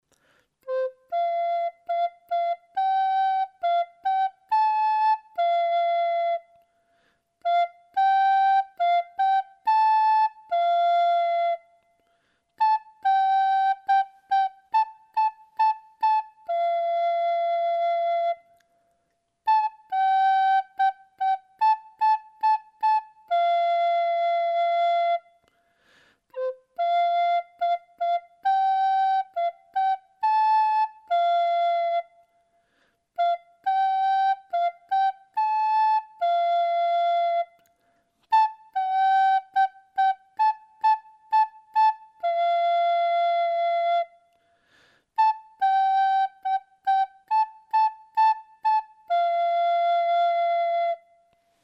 Agora practicaremos unha melodía integrando as notas DO e RE.